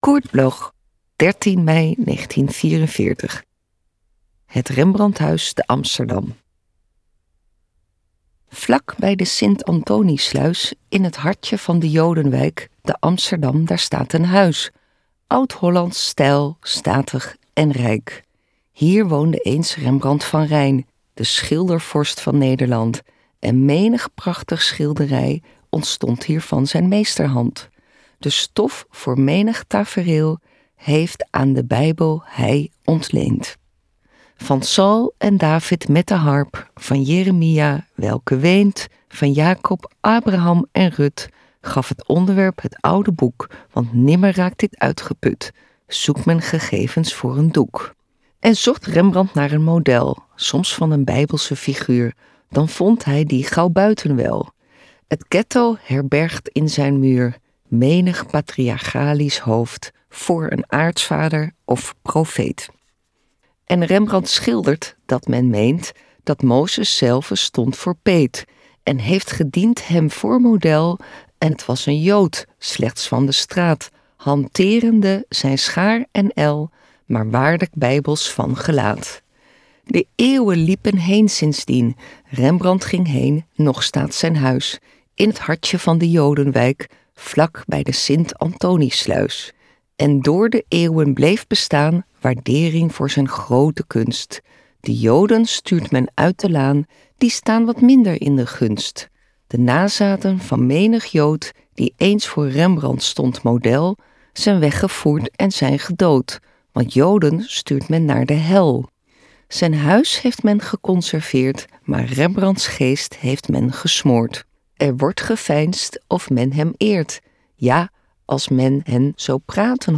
recorded at Karakter sound, Amsterdam